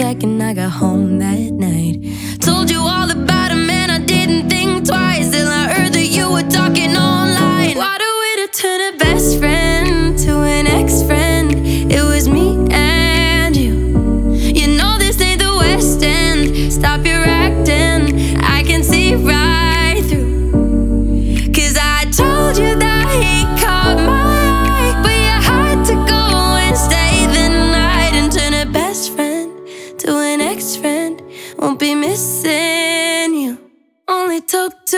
Singer Songwriter